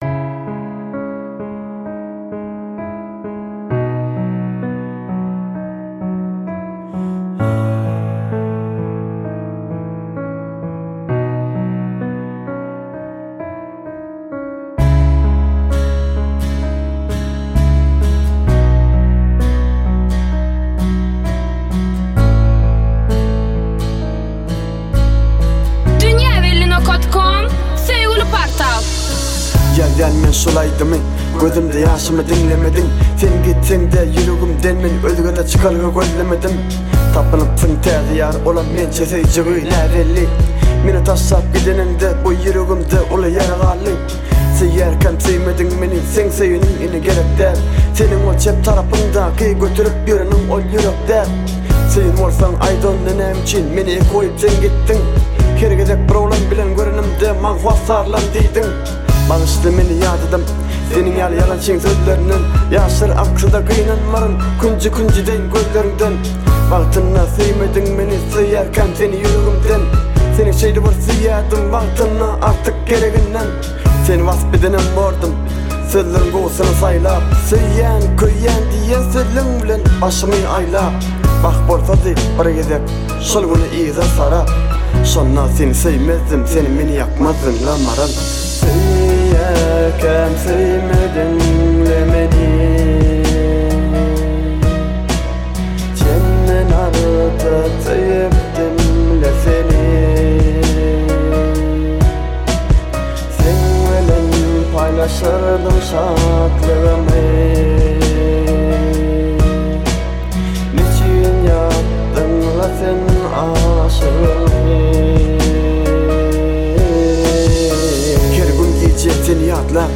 Bölüm: Türkmen Aýdymlar / Rep